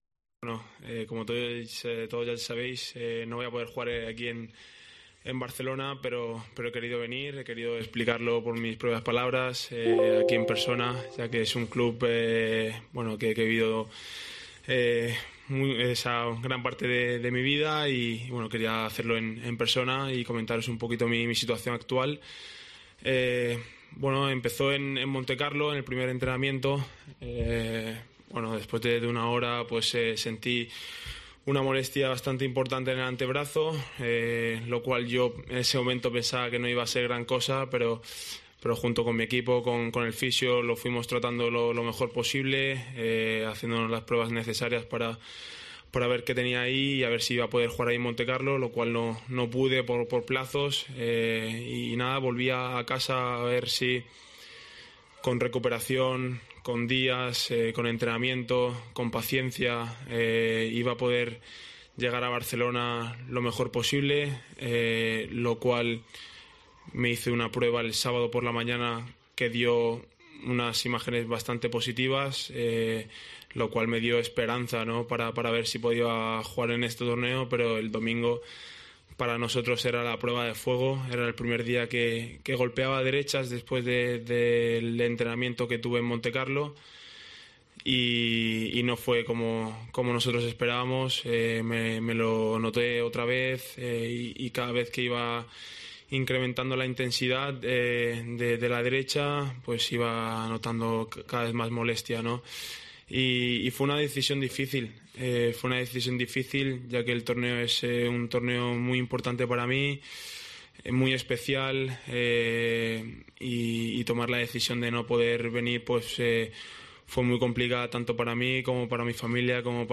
Alcaraz compareció en la sala de prensa del RCT Barcelona-1899 para explicar cómo se encuentra de la pequeña lesión en el antebrazo derecho que le impidió jugar en Montecarlo y que le ha obligado también a renunciar a jugar en la capital catalana.